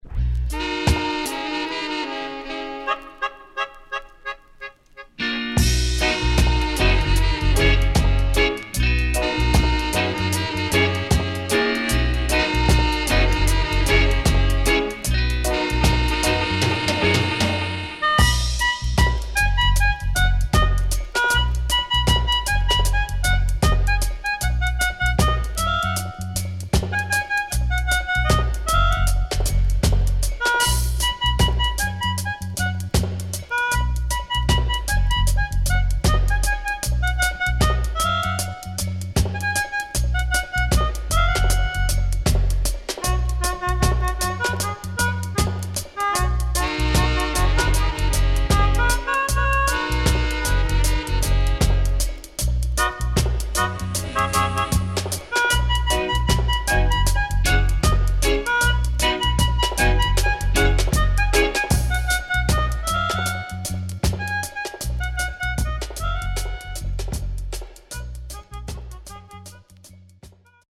CONDITION SIDE A:VG+
SIDE A:うすいこまかい傷ありますがノイズあまり目立ちません。